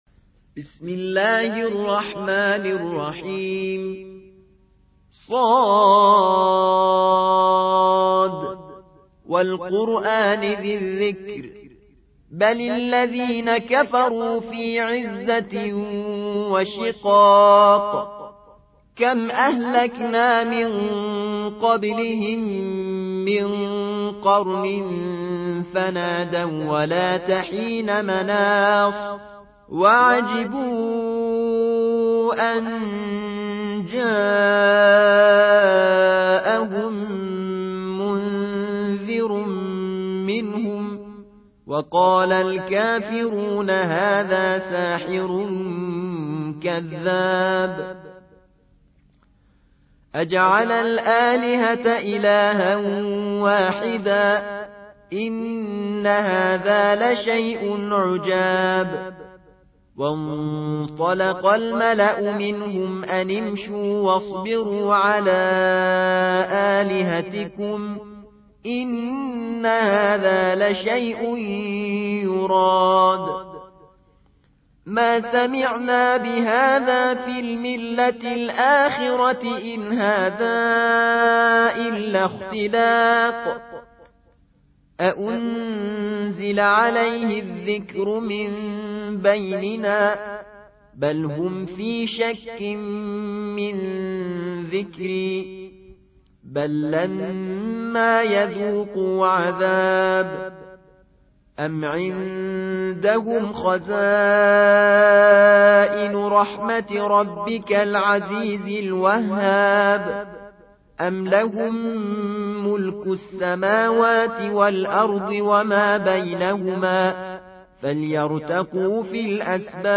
38. سورة ص / القارئ